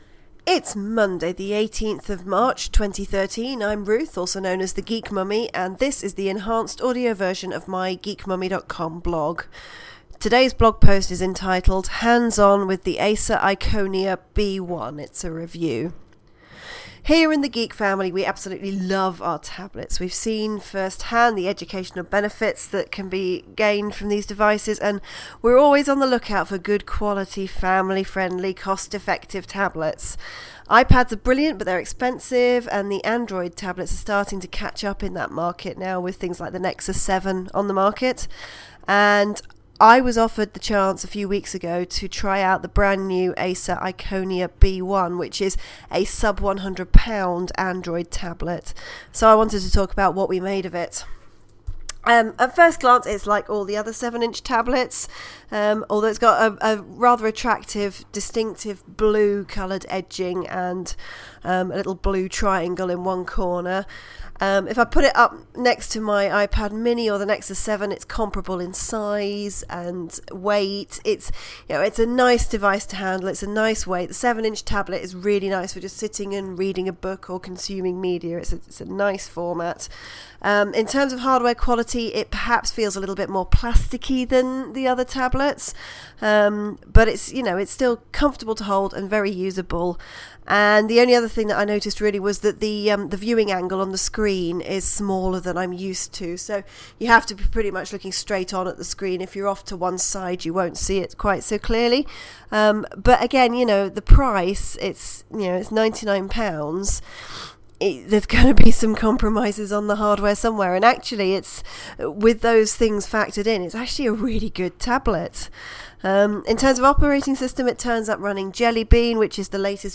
This is a hands-on review of the Acer Iconia B1 - the low cost, sub-£100 Android tablet.